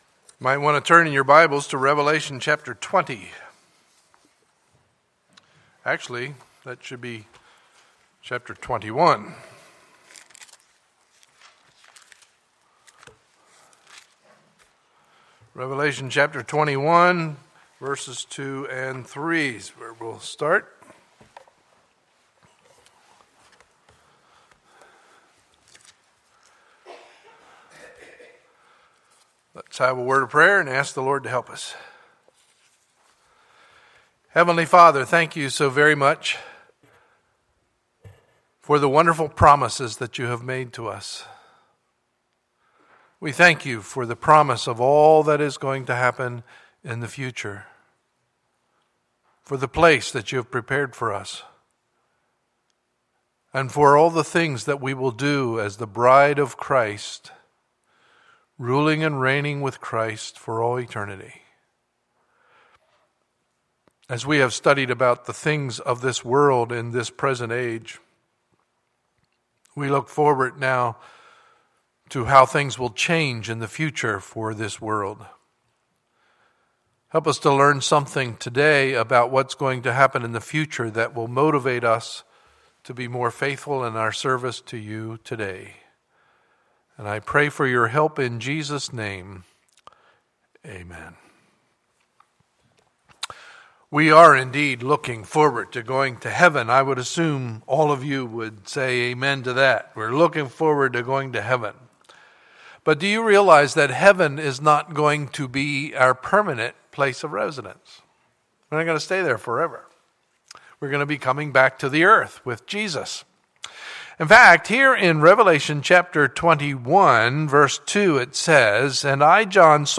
Sunday, November 2, 2014 – Sunday Morning Service